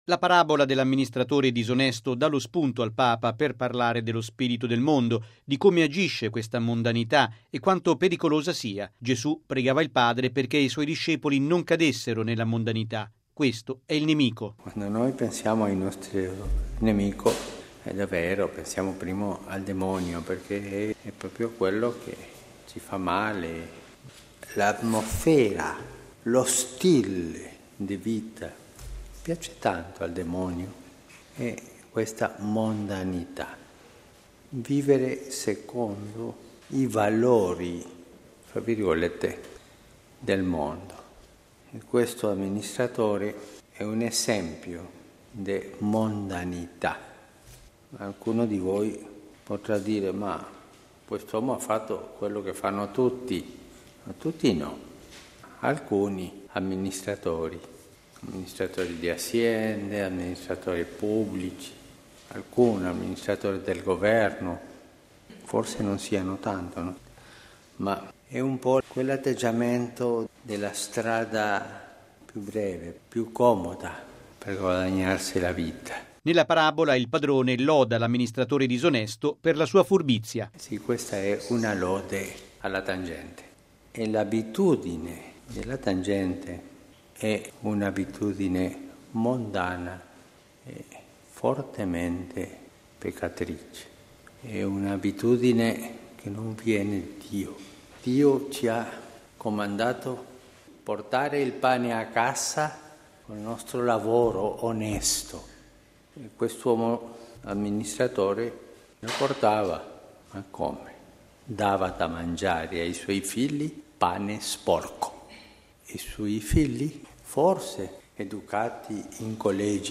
◊   Stamani, durante la Messa celebrata nella Cappellina di Santa Marta, il Papa ha pregato per i tanti giovani che ricevono dai genitori “pane sporco”, guadagni frutto di tangenti e corruzione, e hanno fame di dignità perché il lavoro disonesto toglie la dignità.